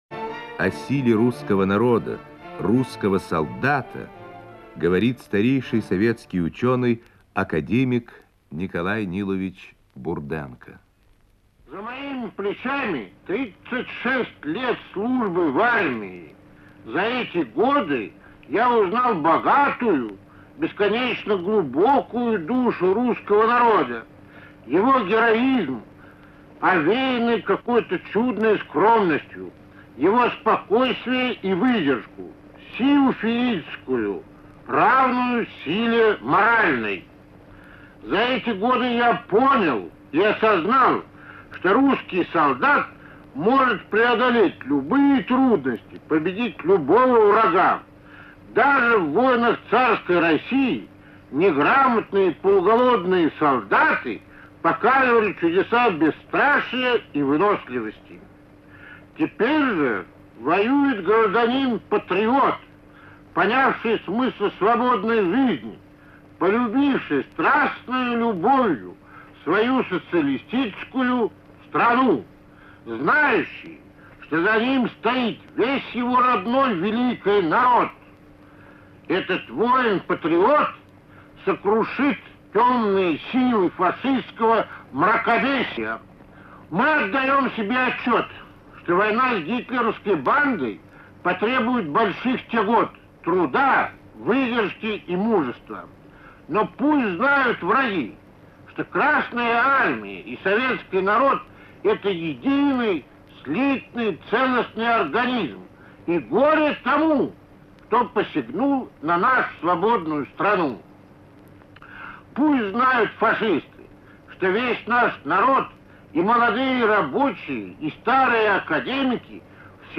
Представляем вашему вниманию архивную запись, сделанную в 1942 году. Знаменитый советский ученый, врач, академик Николай Бурденко рассказывает о силе русского солдата.